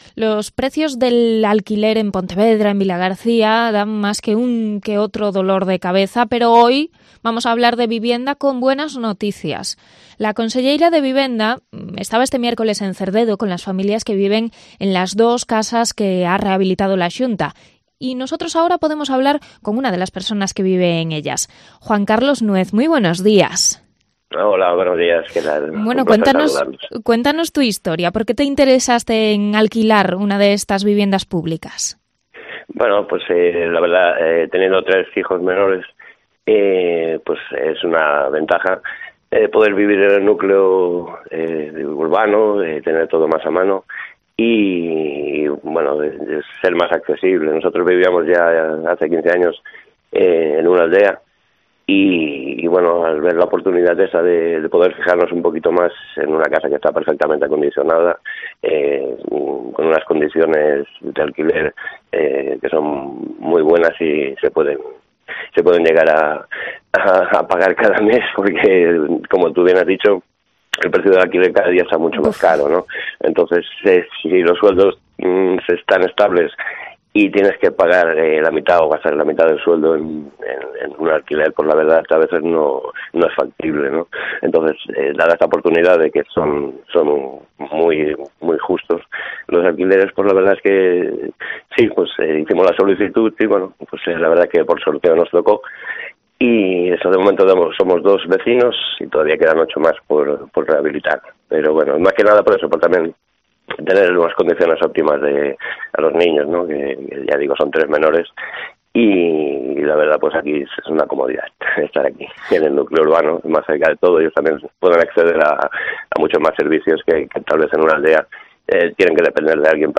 Entrevista a uno de los beneficiarios de vivienda pública en Cerdedo